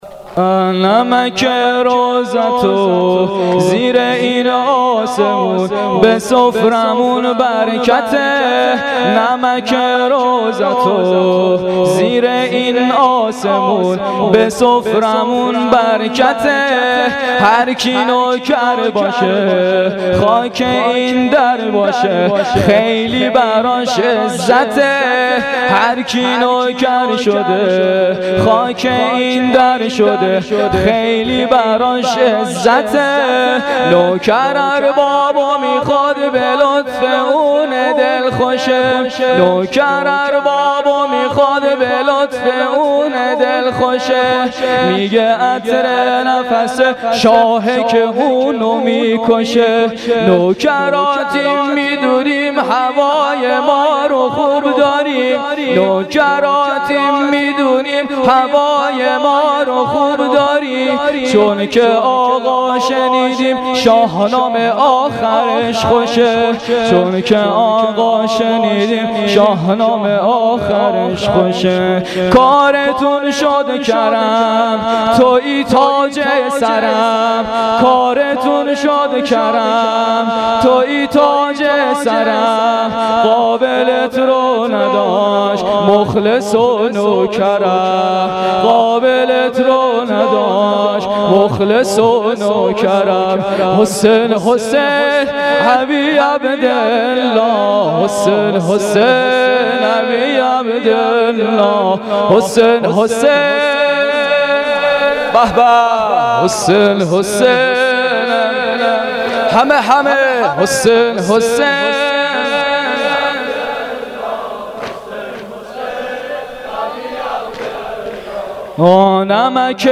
• مداحی
شور